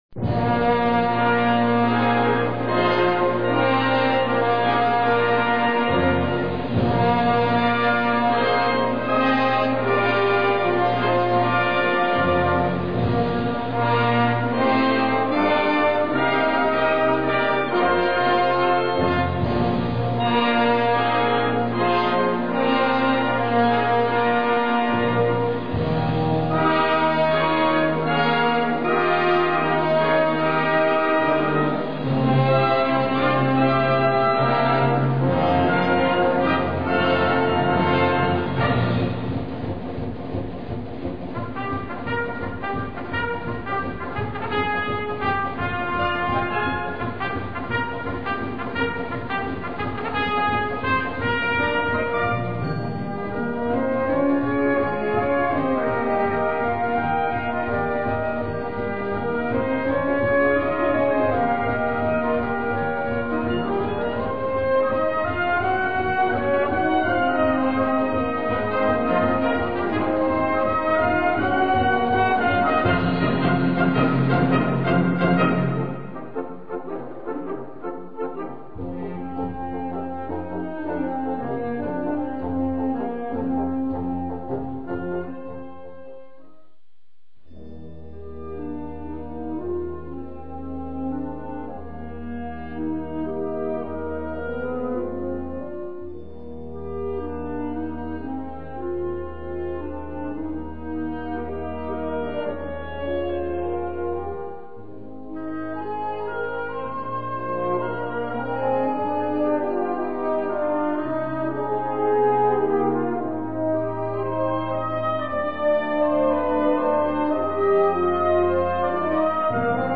Catégorie Harmonie/Fanfare/Brass-band
Sous-catégorie Ouvertures (œuvres originales)
Instrumentation Ha (orchestre d'harmonie)